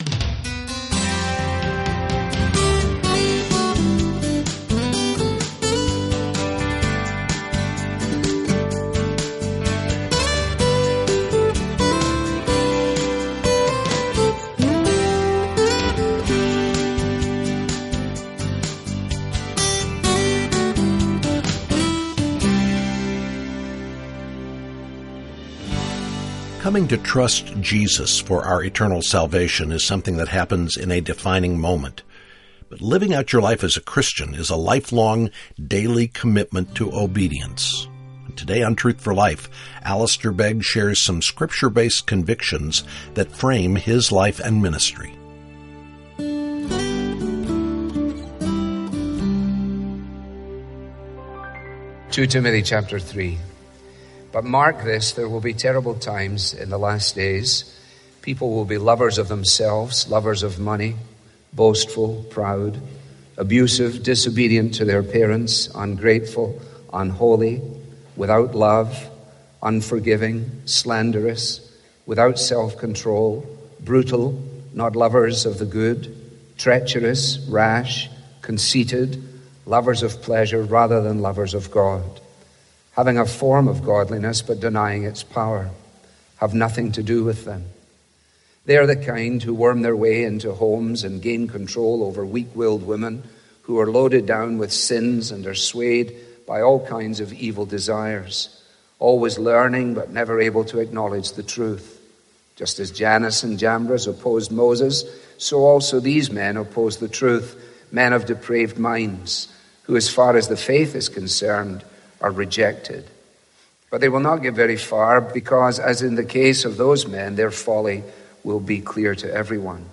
While coming to faith in Jesus can happen in a defining moment, living as a Christian is a lifelong, daily commitment to obedience. Listen to Truth For Life as Alistair Begg shares some Scripture-based convictions that have framed his life and ministry.
Helpful Resources - Learn about God's salvation plan - Read our most recent articles - Subscribe to our daily devotional Follow Us YouTube | Instagram | Facebook | Twitter This listener-funded program features the clear, relevant Bible teaching of Alistair Begg.